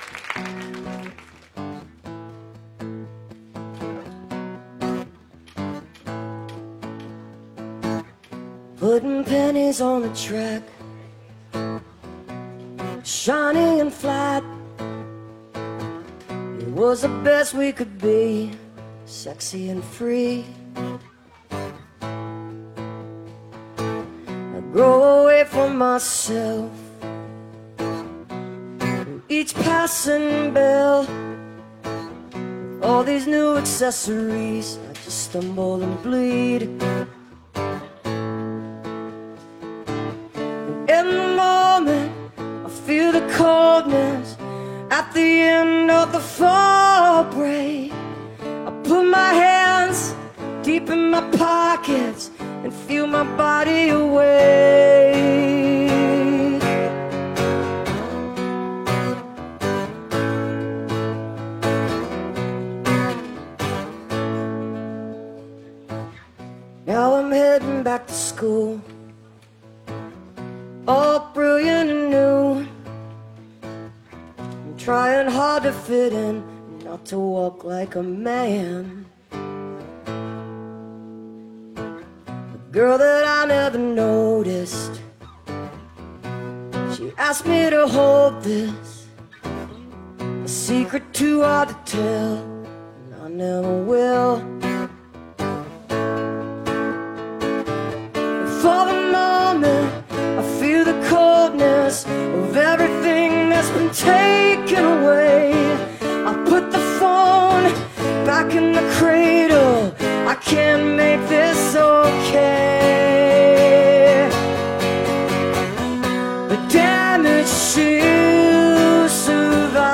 (captured from a youtube video)